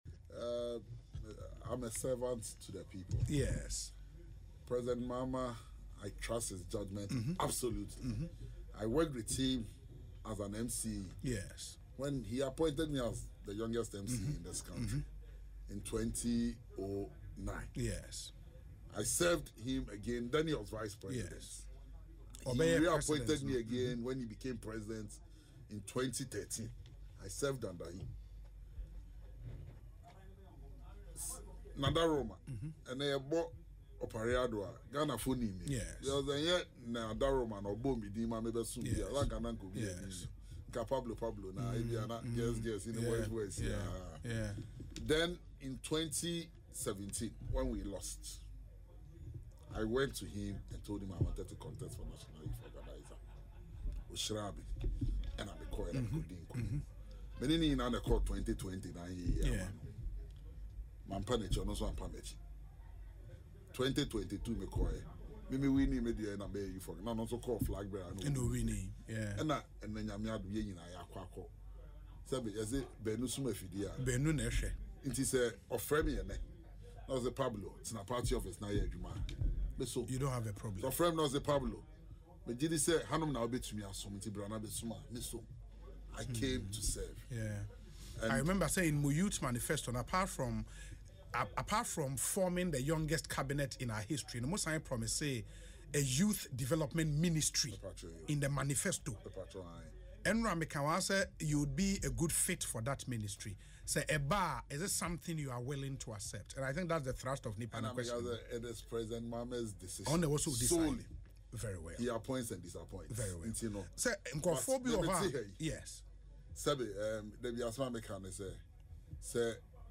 He revealed this in an interview on Asempa FM’s Ekosii Sen, stressing he has absolute confidence in Mahama to make the best decisions.